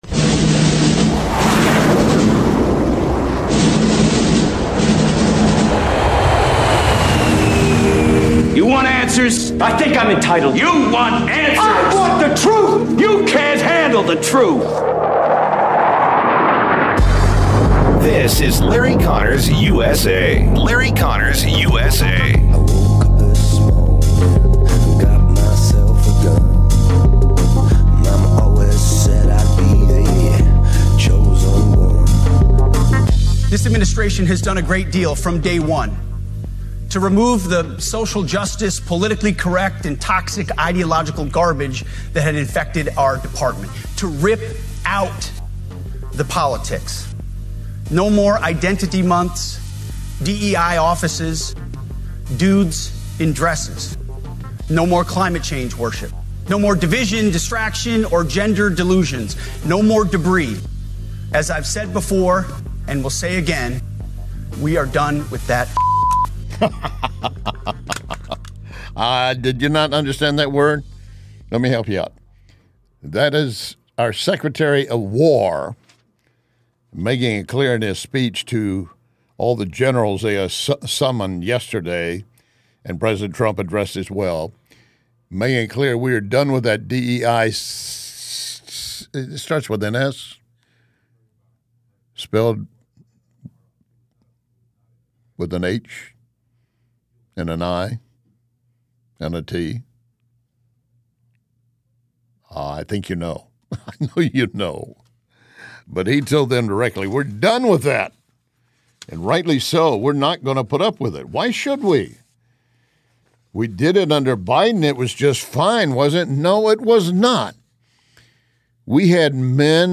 Guest: Mitchell Silk RUMBLE